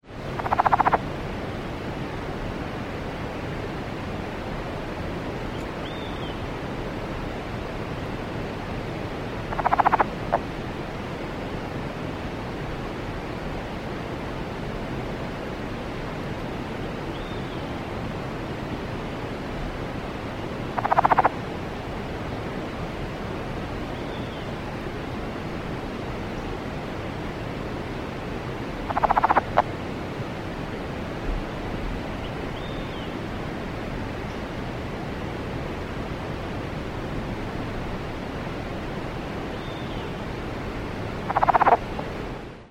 The advertisement call of Rana cascadae is a quiet series of low grating clucking noises.
The following sounds were recorded on a sunny afternoon in early July at approx. 5500 ft. in Pierce County Washington.
The sounds of running water, the occasional song of an Olive-sided Flycatcher and other birds, and insects can be heard in the background.
Sounds This is 43 seconds of the advertisement calls of one frog.